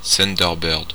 Ääntäminen
Synonyymit oiseau-tonnerre Ääntäminen France (Normandie): IPA: /sœn.dœʁ.bœʁd/ Tuntematon aksentti: IPA: /tœn.dœʁ.bœʁd/ Haettu sana löytyi näillä lähdekielillä: ranska Käännöksiä ei löytynyt valitulle kohdekielelle.